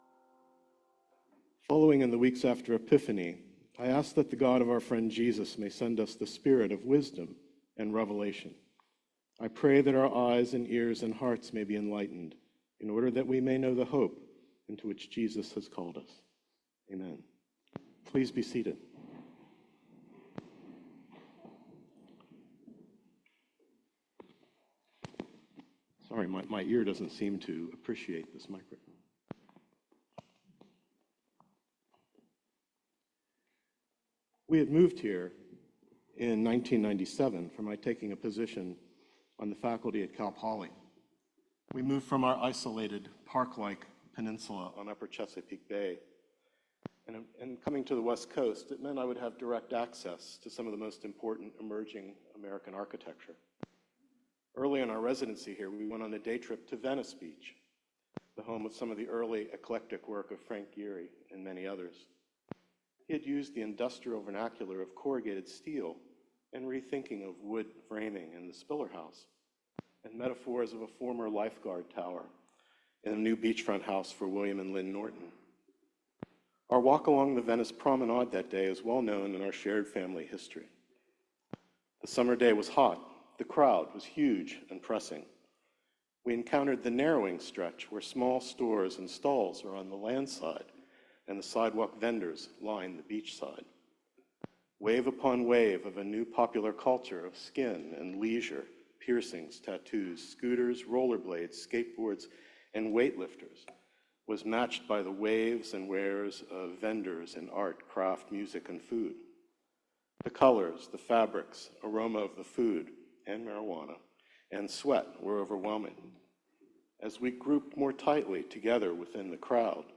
Sermon-6-30.mp3